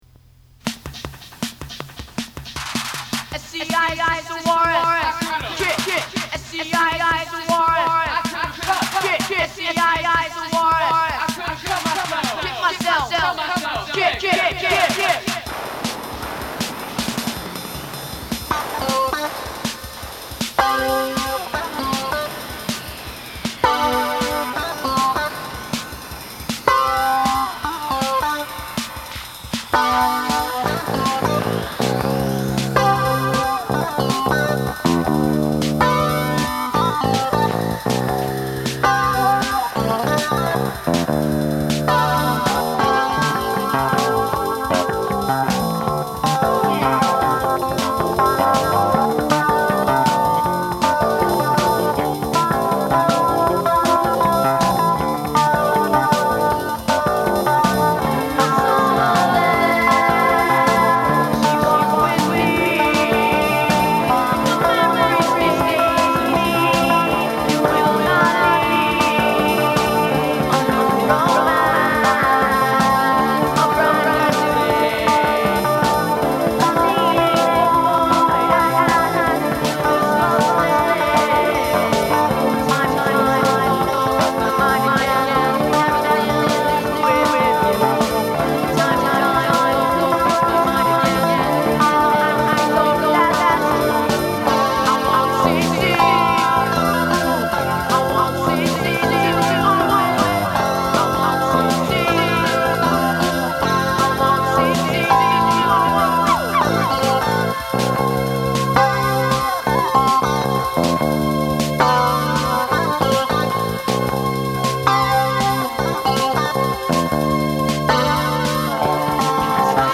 1 Intro / I Want LIVE |